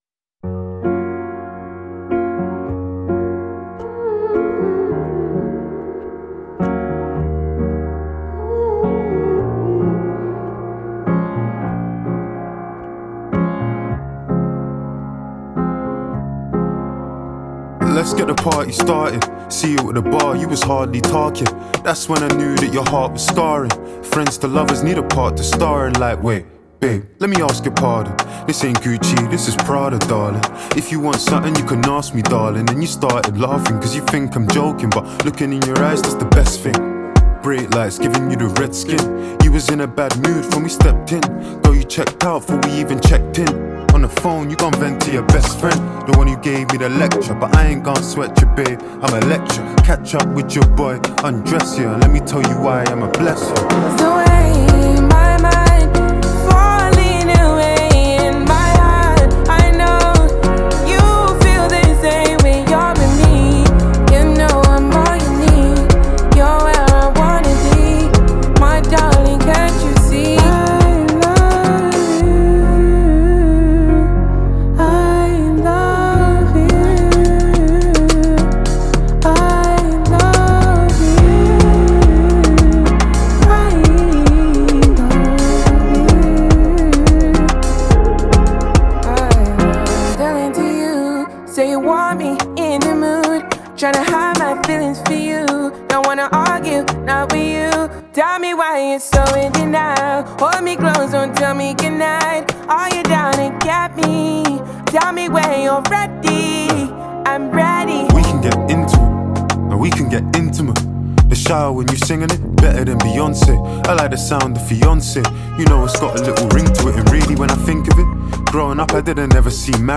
British rapper